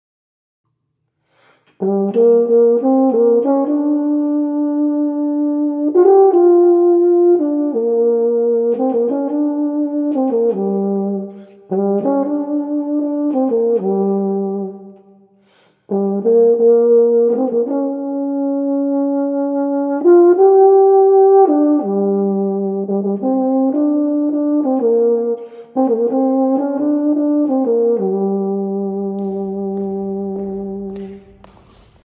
Euphonium
Samen met mijn grote broer de tuba en mijn neef de sousafoon behoren wij tot de groep ‘zwaar koper’.